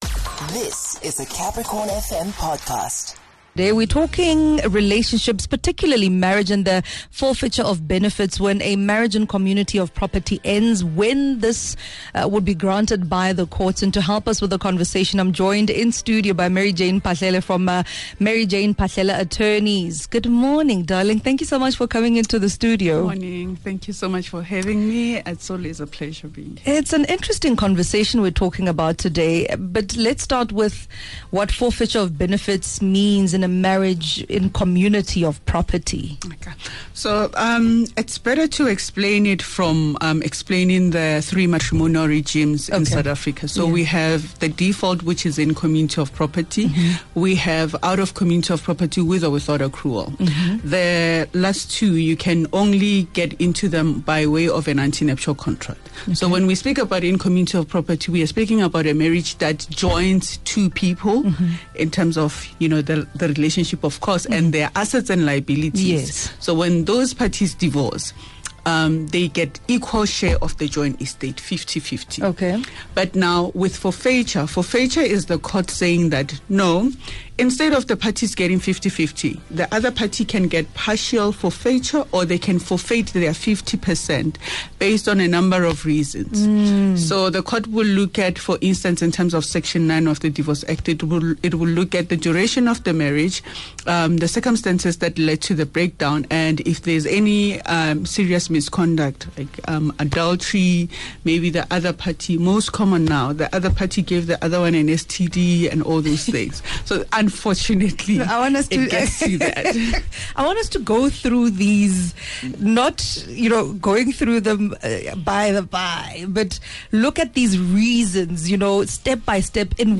10 Feb Conversation on what forfeiture of benefits means and when it may apply during a divorce.